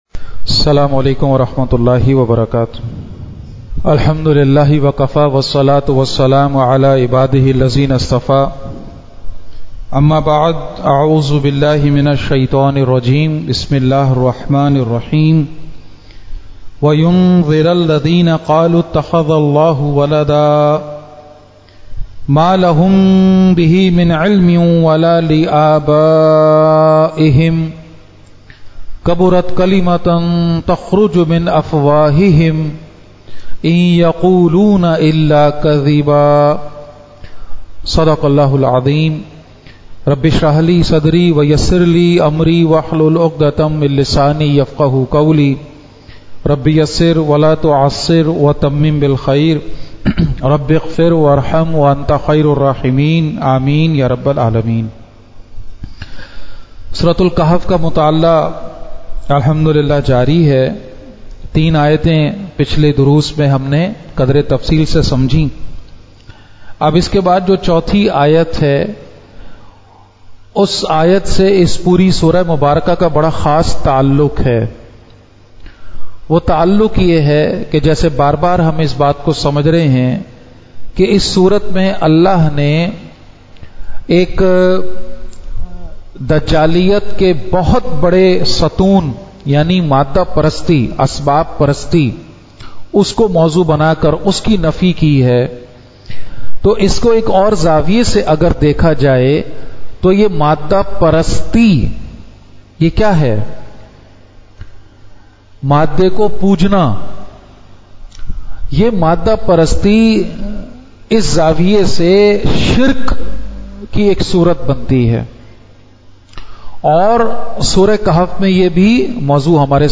at Masjid Jame Al-Quran, Quran Institute Johar.
Khutbat-e-Jummah (Friday Sermons)